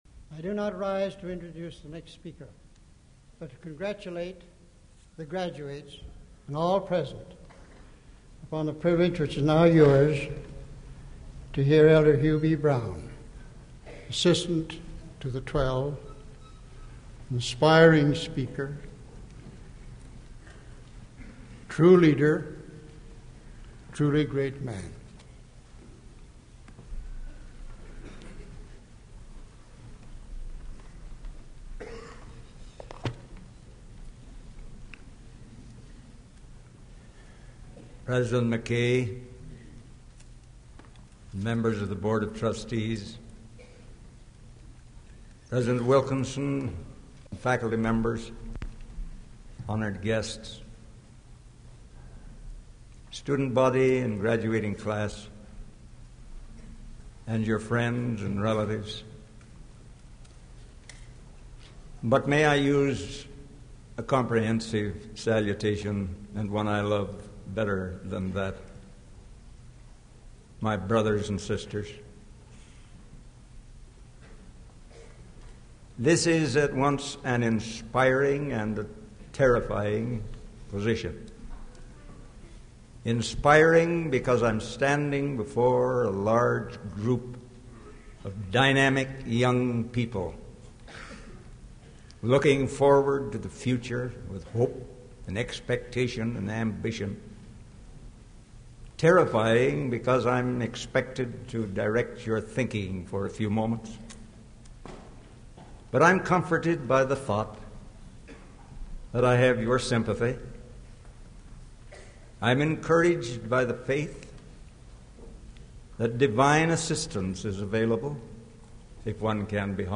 Audio recording of Discover and Actualize Your Potential Self by Hugh B. Brown
Assistant to the Quorum of the Twelve Apostles